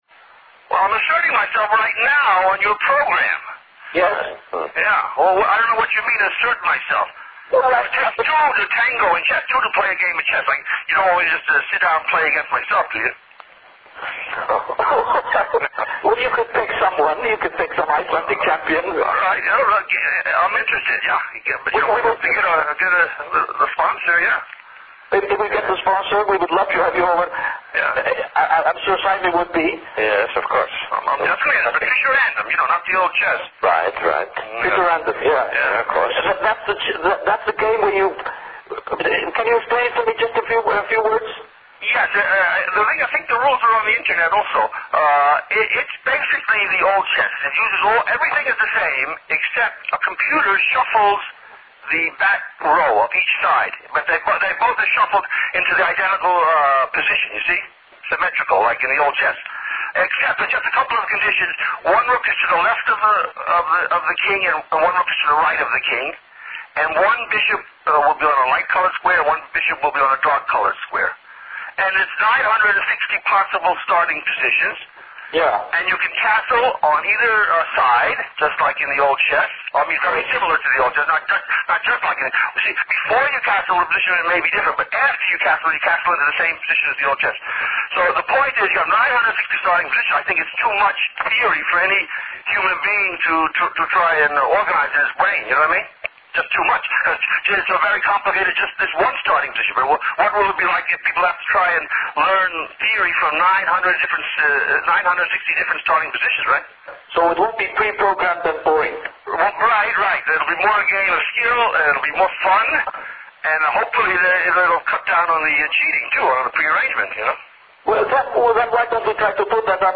At a couple of points, Fischer had to be warned for his excessive profanity.
Contains very strong language and may be offensive to some.
Icelandic 27 January 2002 Interview (Part 1 |